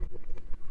描述：搅动垒球 砰砰的撞击运动
标签： 扑通 搅拌 棒球 凸块
声道立体声